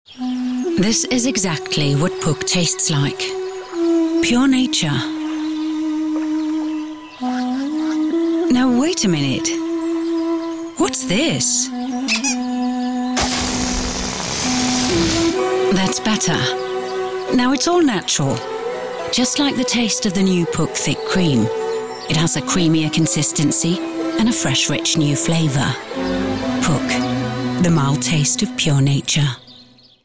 Profi Sprecherin dänisch. Natural speech. Warm, Persuasive, Mature, Narration, commercials, telephone systems
Sprechprobe: Sonstiges (Muttersprache):
Highly experienced professional female danish voice over artist. Natural speech. Warm, Persuasive, Mature, Narration, commercials, telephone systems